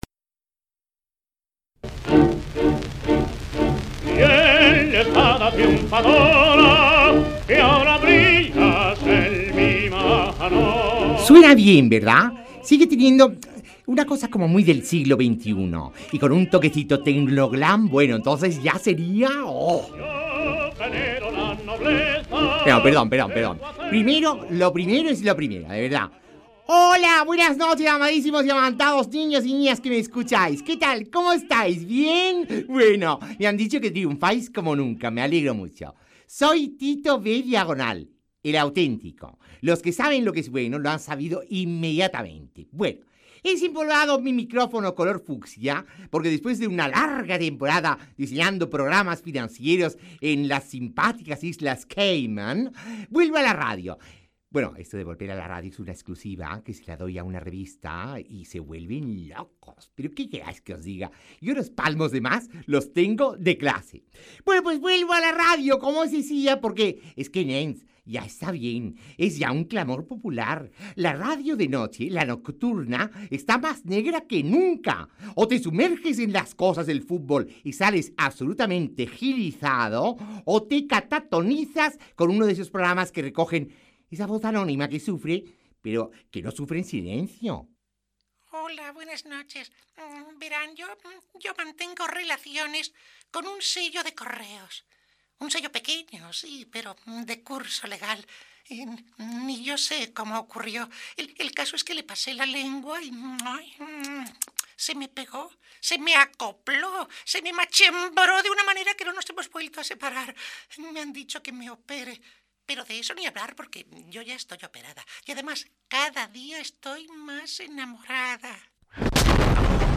Promoció prèvia a l'emissió del primer programa.
Entreteniment
FM